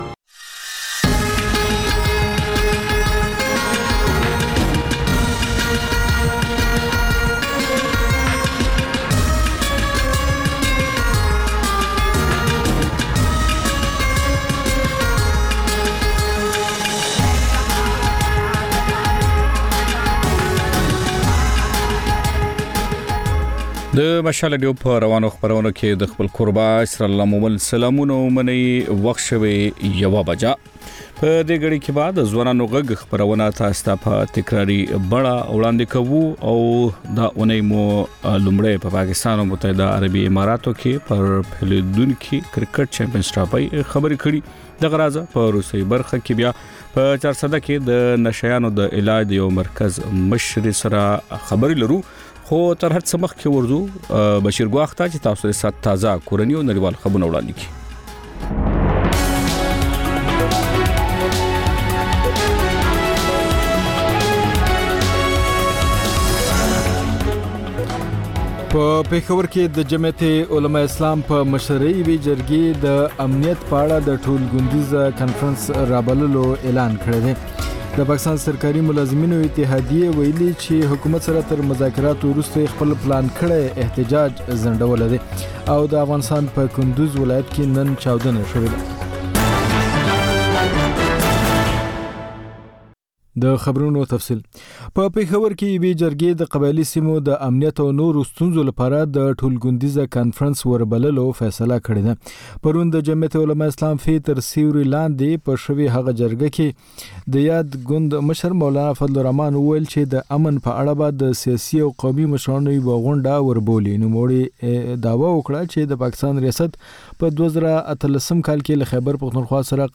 د مشال راډیو لومړۍ ماسپښينۍ خپرونه. په دې خپرونه کې تر خبرونو وروسته بېلا بېل رپورټونه، شننې، مرکې خپرېږي. ورسره اوونیزه خپرونه/خپرونې هم خپرېږي.